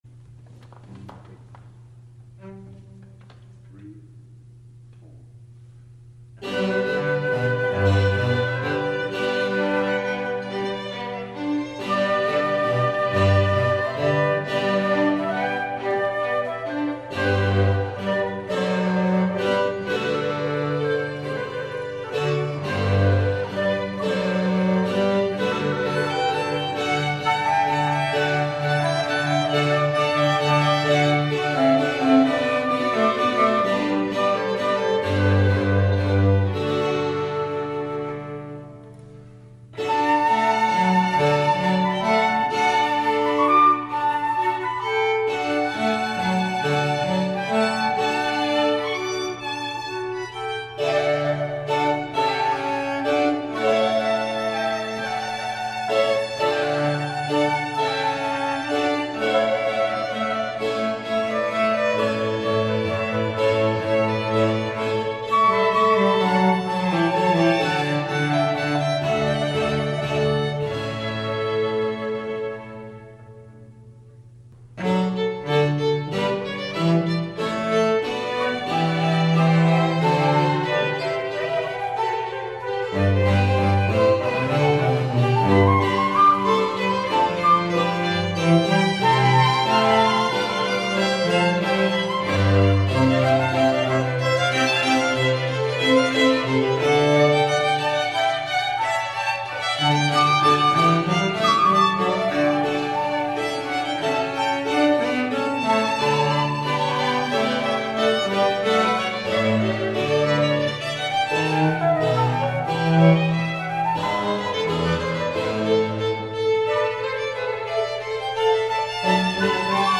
Two pieces we did at Christmas for two violins, flute, harpsichord, and two cellos.
chamber music tellamnn.mp3